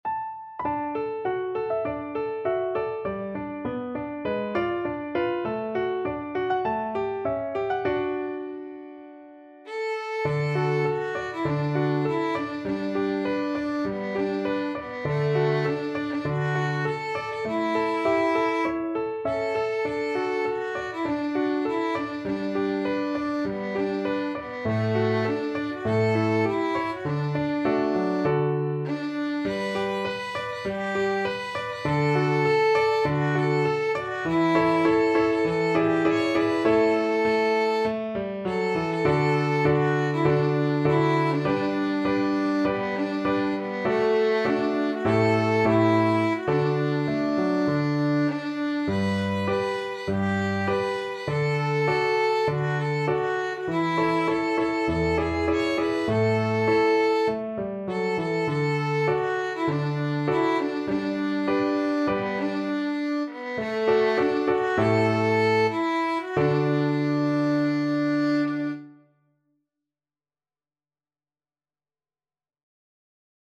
Violin
4/4 (View more 4/4 Music)
D major (Sounding Pitch) (View more D major Music for Violin )
~ = 100 Moderato
Traditional (View more Traditional Violin Music)